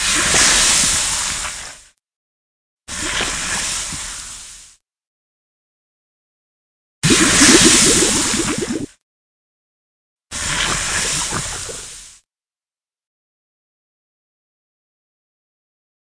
lava1.ogg